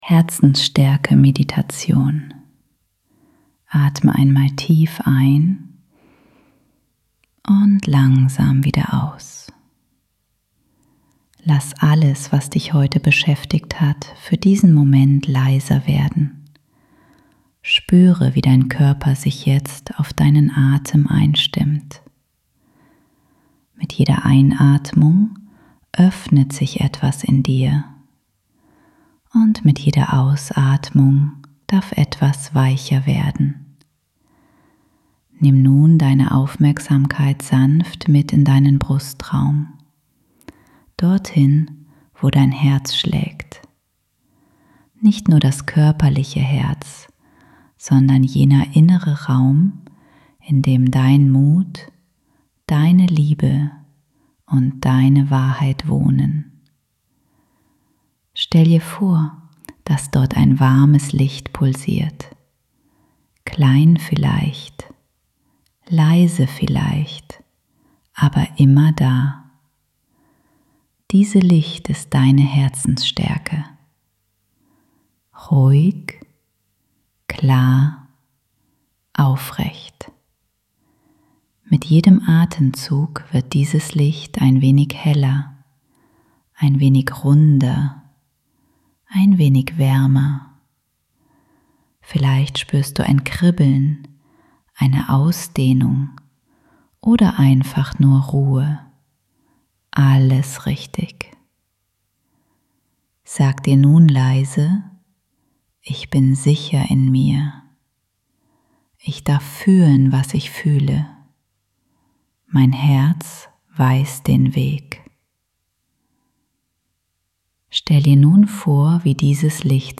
2. Für diesen Seelenmärchen  ist hier Deine Herzens-Reise -als eine gesprochene Meditation